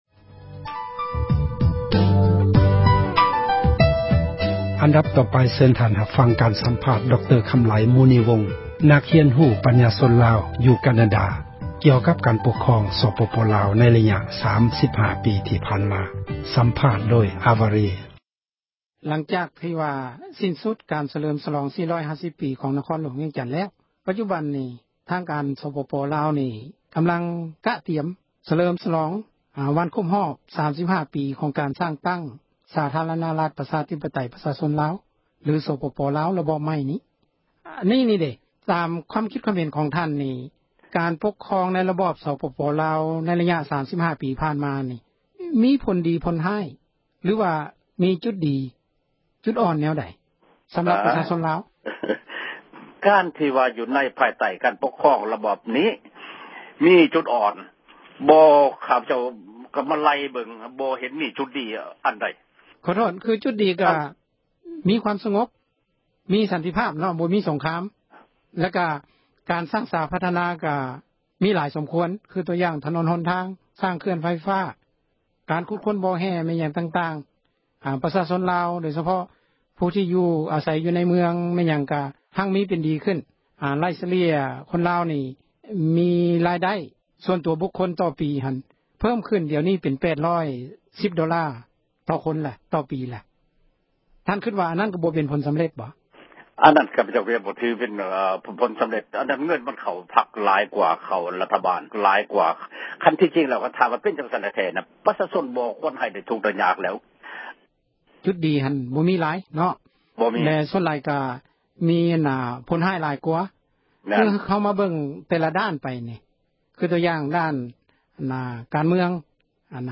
ສໍາພາດ ດຣ.